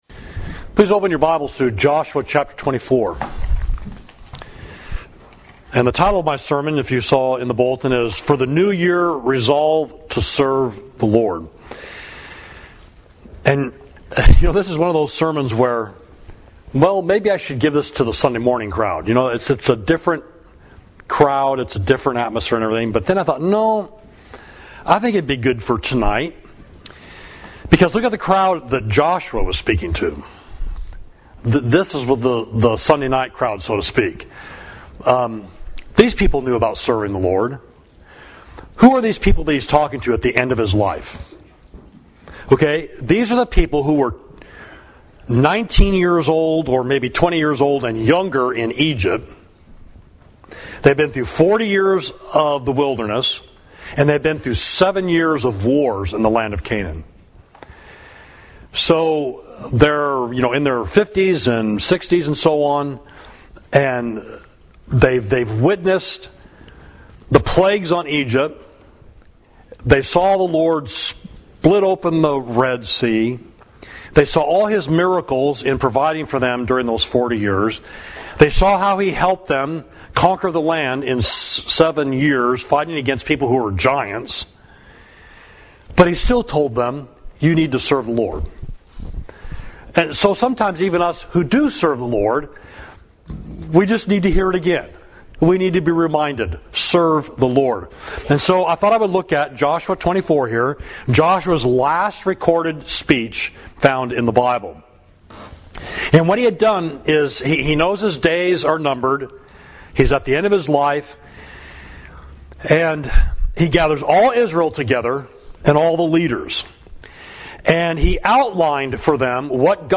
Sermon: For the New Year Resolve to Serve the Lord – Savage Street Church of Christ